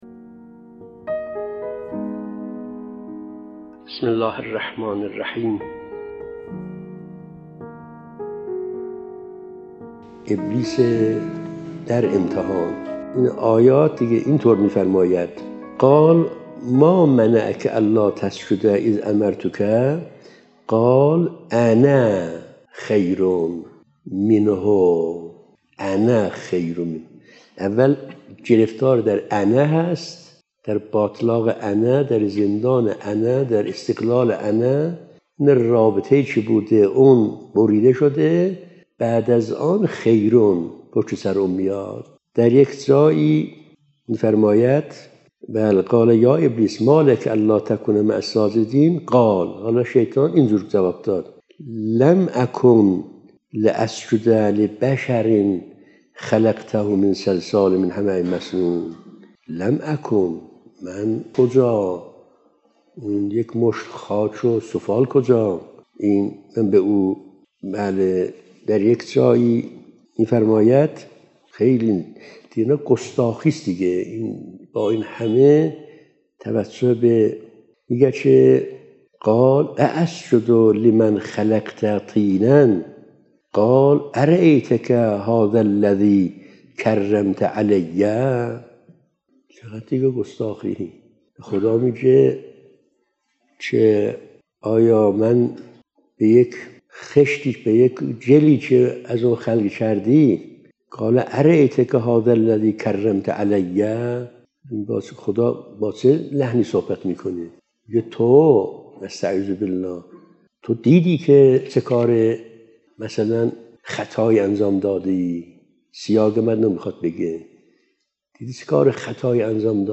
📍از جلسه اولین جمعه ماه قمری| ماه رجب 🎙ابلیس (۶) 📌ابلیس در امتحان ⏳۶ دقیقه 🔗پیوند دریافت👇 🌐